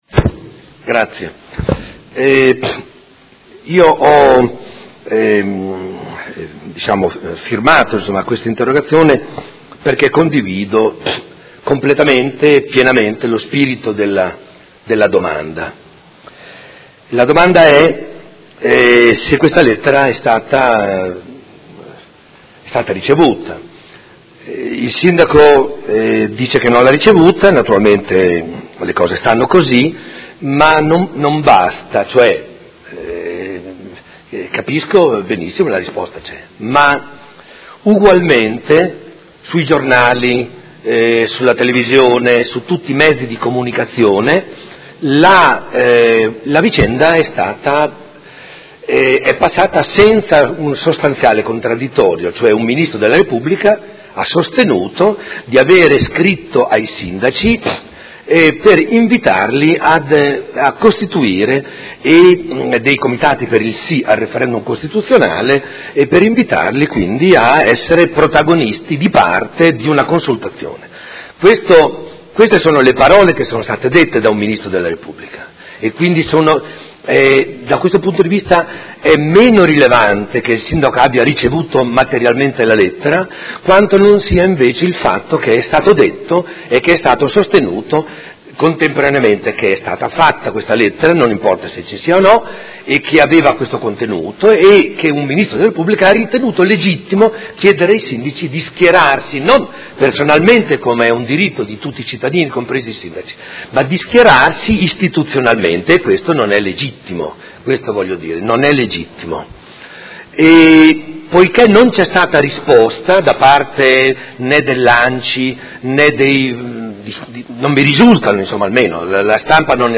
Seduta del 22/09/2016 Interrogazione del Consigliere Rocco (FAS-SI) e del Consigliere Campana (PerMeModena) avente per oggetto: Lettera del Ministro delle Infrastrutture Del Rio. Dibattito